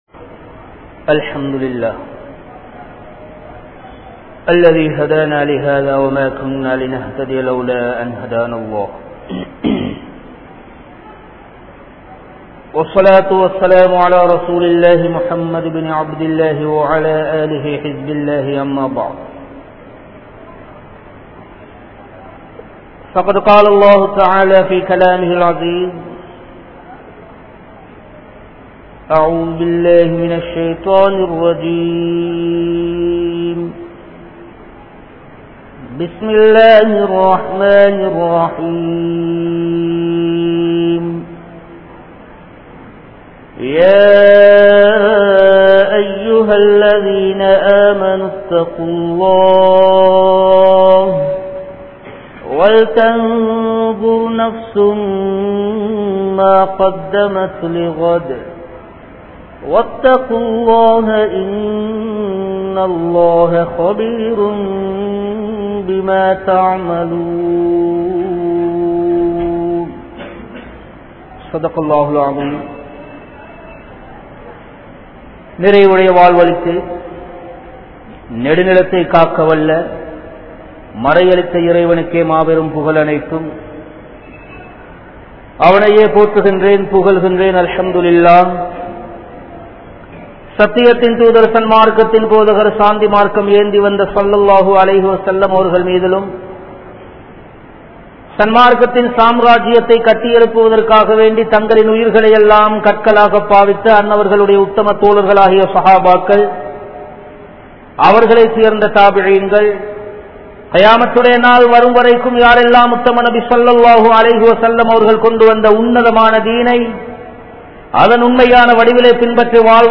Maranaththin Pin Ulla Vaalkai (மரணத்தின் பின்னுள்ள வாழ்க்கை) | Audio Bayans | All Ceylon Muslim Youth Community | Addalaichenai
Muhiyaddeen Grand Jumua Masjith